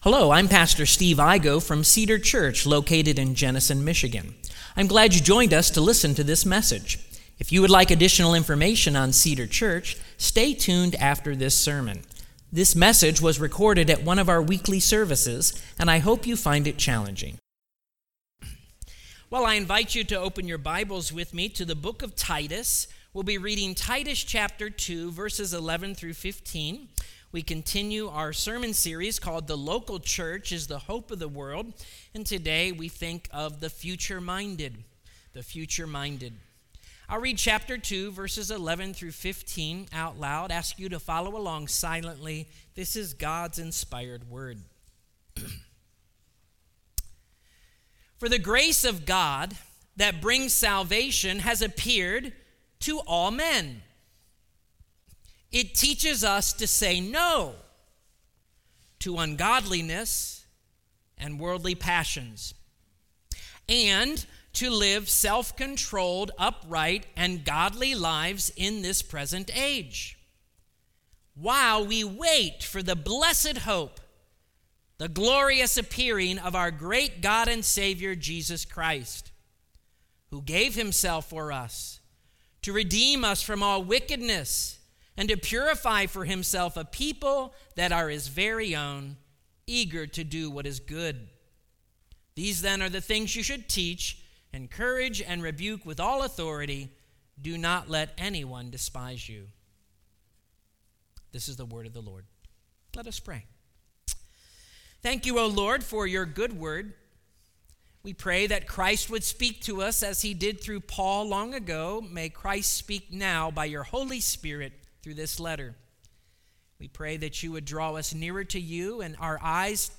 Sermons on Titus